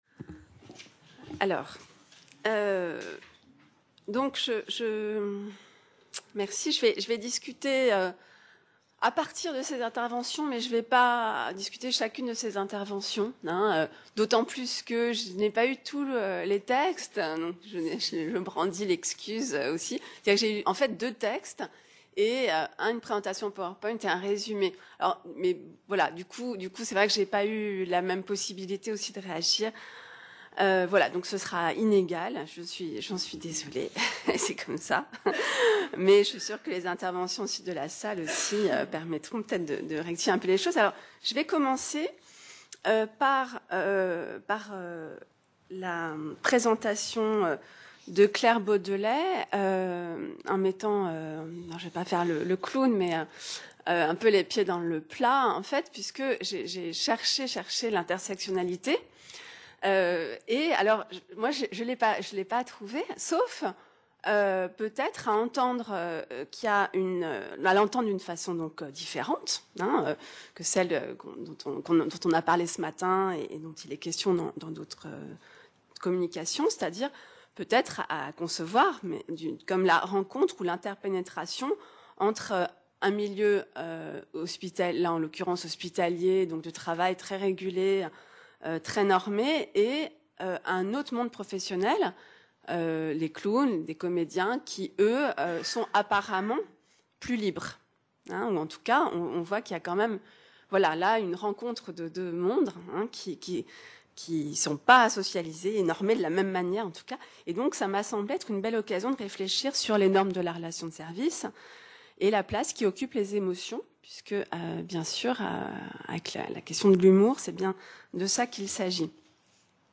Relation de service ou servitude ? - Discussion | Canal U